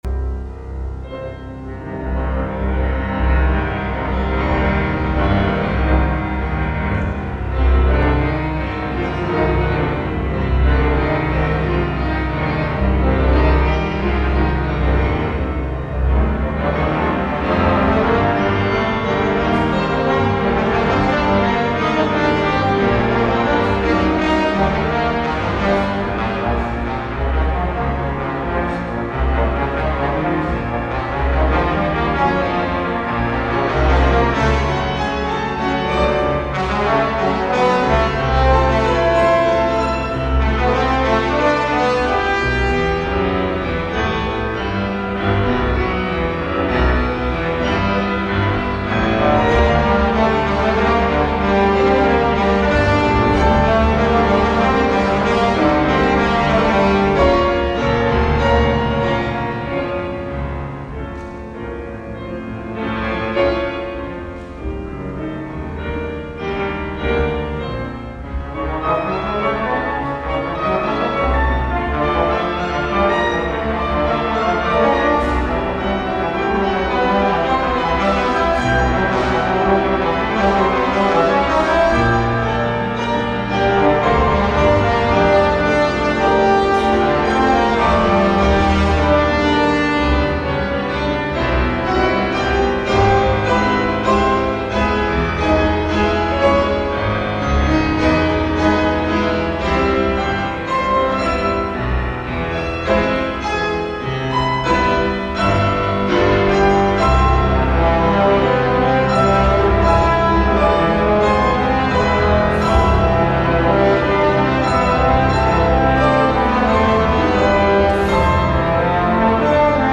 PIANO REDUCTION
18′           tenor-trombone, piano